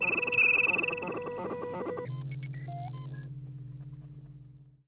On the main flight deck, the ship's computer springs into life.......